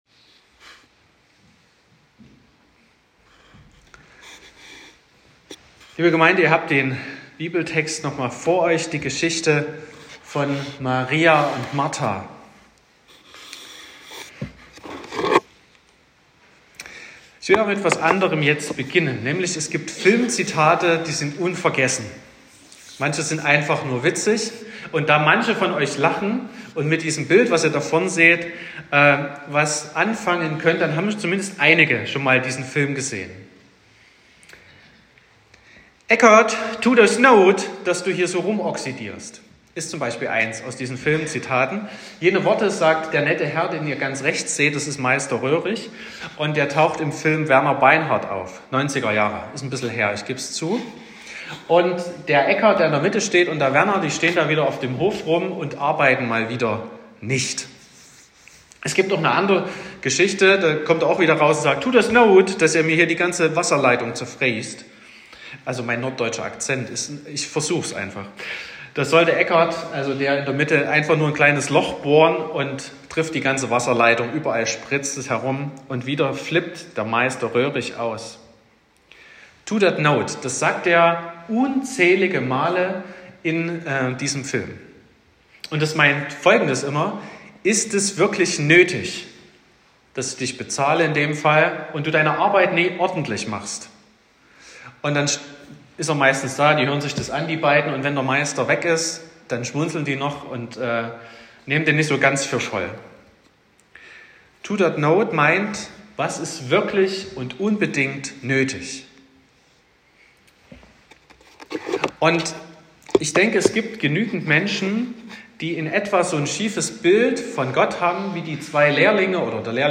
02.03.2025 – Gottesdienst
Predigt (Audio): 2025-03-02_Zuhoeren_tut_Not_.m4a (12,9 MB)